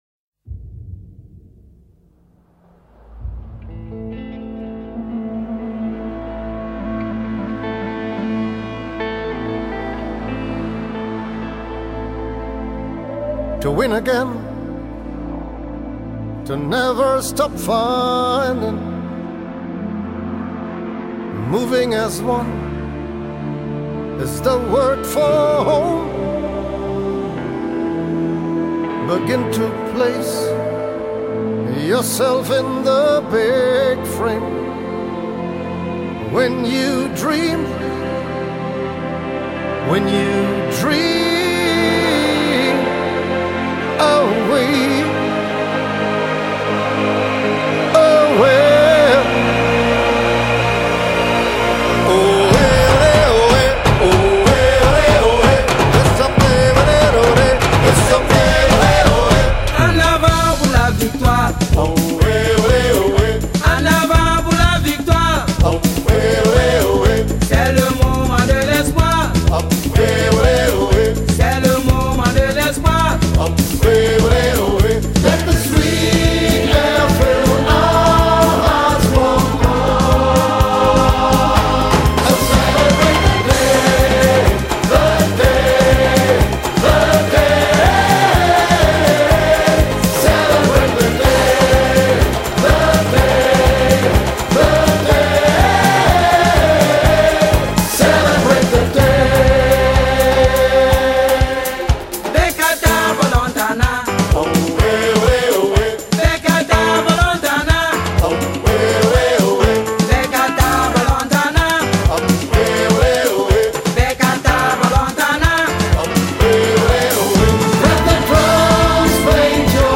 copy URL นี่ไปใส่เลยครับ เป็น wma แต่เสียงก็ชัดดีครับ